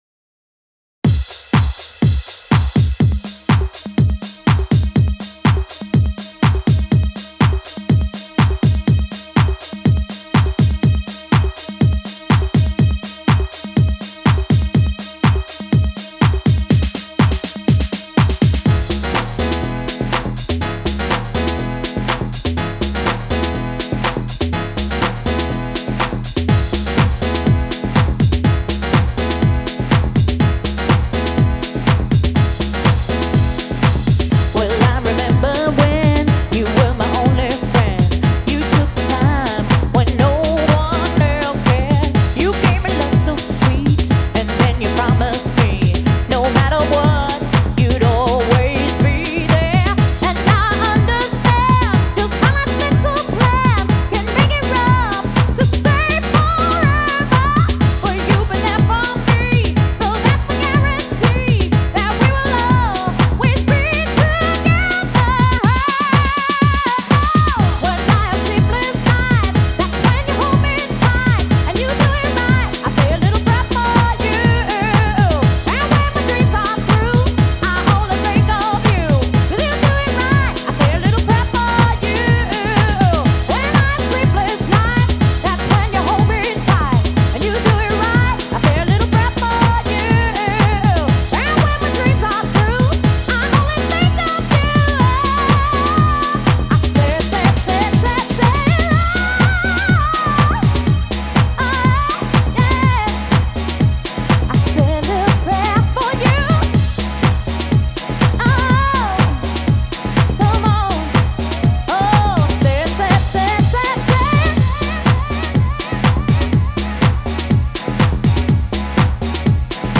BPM123--1
Audio QualityMusic Cut